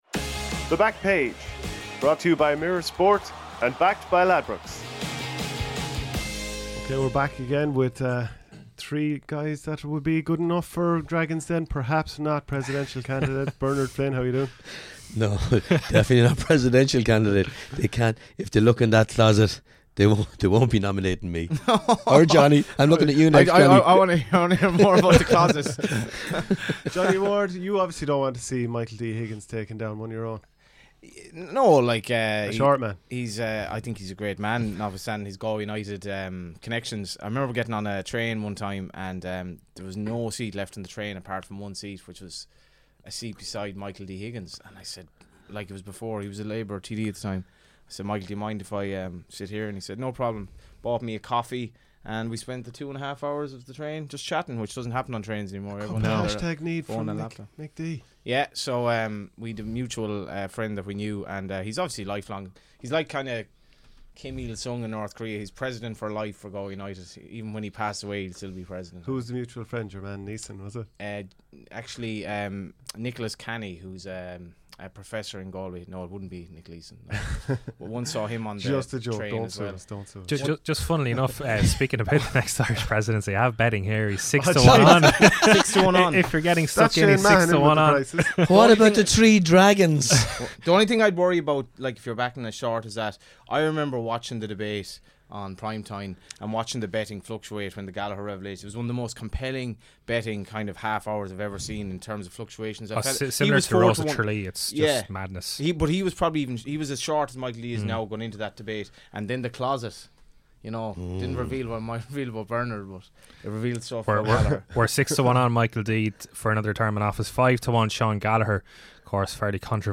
The panel dig deep to find the best value bets to make what promises to be a great game even more exciting.